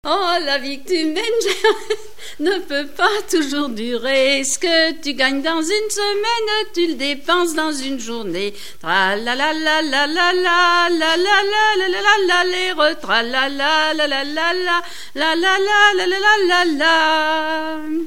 Mémoires et Patrimoines vivants - RaddO est une base de données d'archives iconographiques et sonores.
avant-deux
Chants brefs - A danser
Pièce musicale inédite